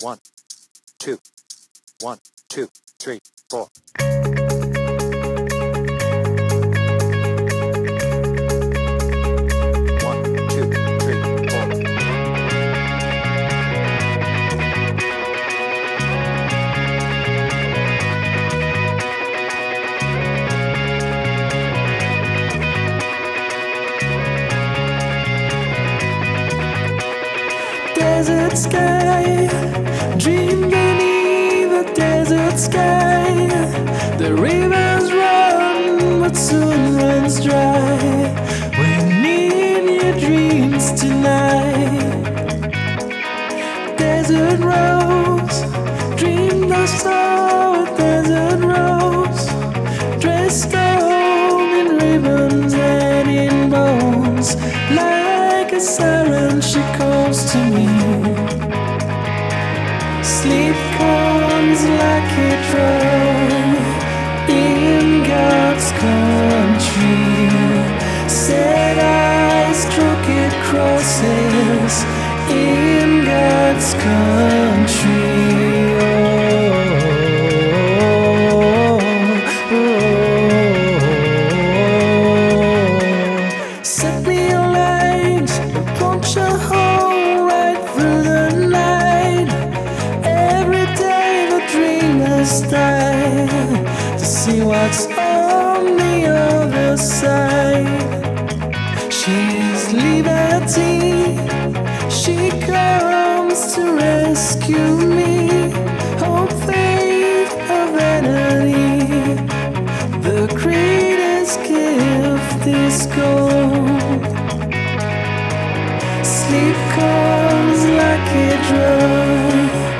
BPM : 120
Tuning : Eb
Without vocals